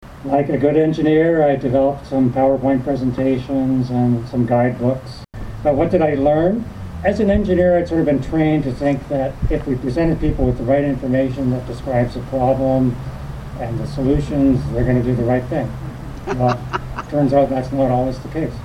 After about an hour of placard-waving surrounding the junction of Highways 60 and 41, the group retired to the serenity of the amphitheatre in Gerald Tracey Park to hear from a variety of speakers including one pragmatic Upper Tier radical.